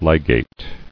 [li·gate]